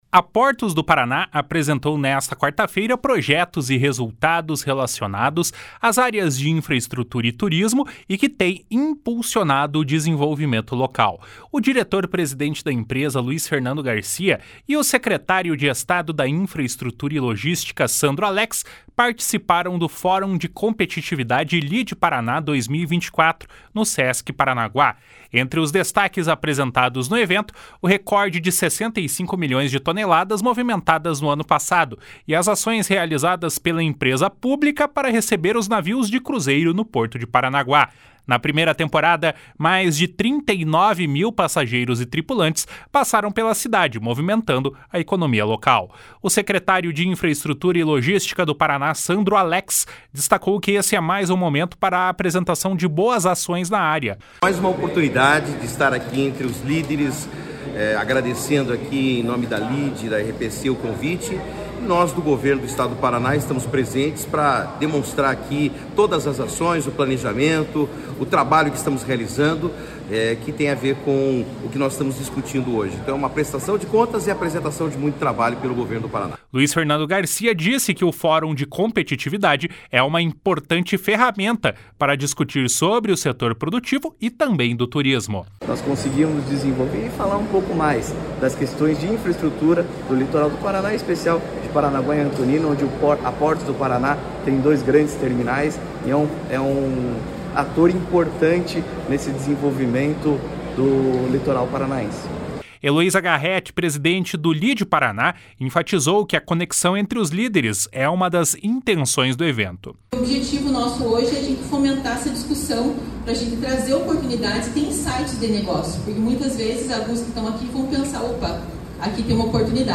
// SONORA LUIZ FERNANDO GARCIA //